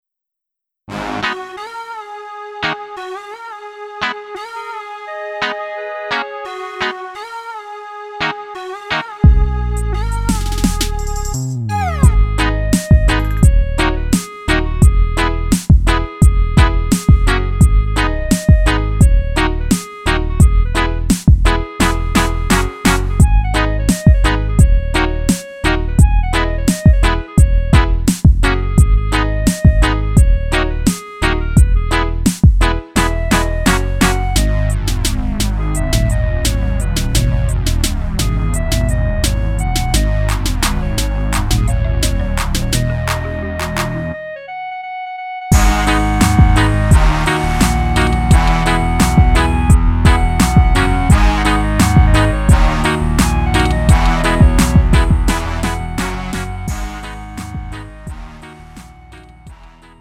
음정 원키 3:11
장르 가요 구분